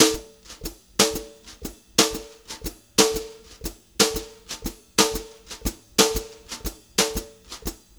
120JZBEAT3-L.wav